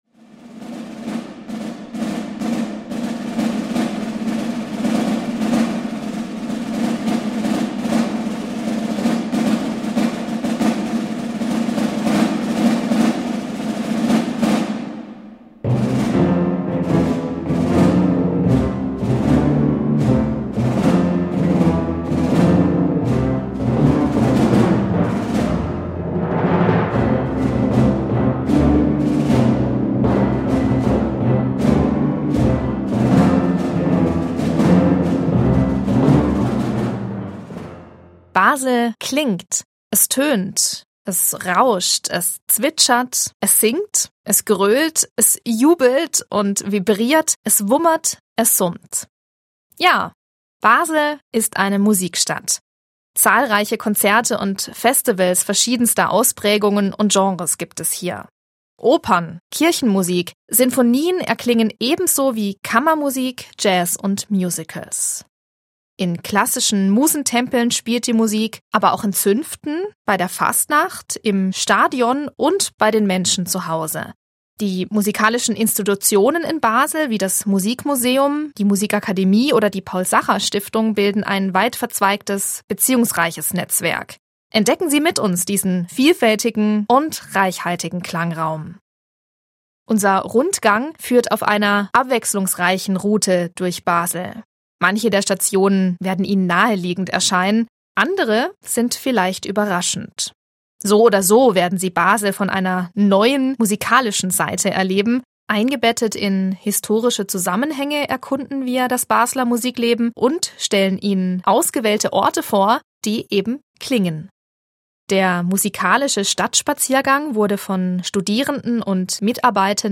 Basel klingt!
QUELLEN der Musikbeispiele: